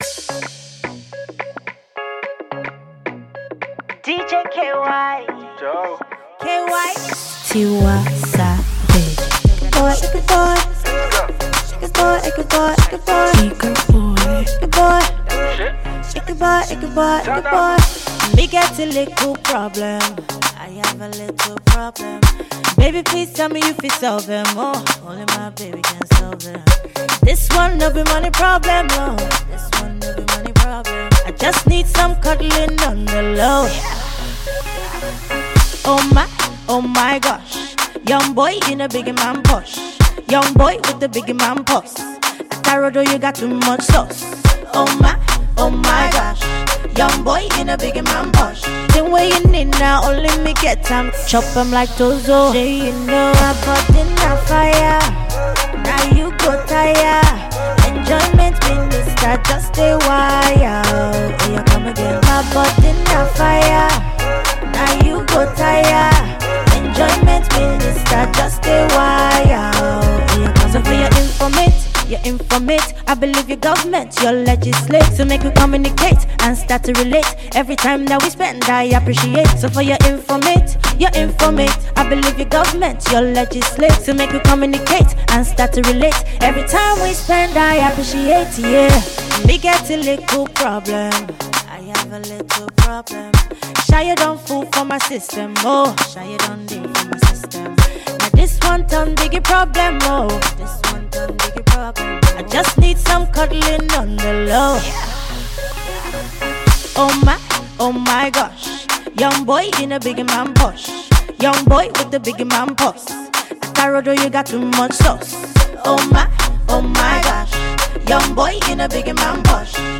banging new tune